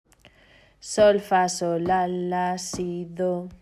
O erro que se comete:
mal_dispar.mp3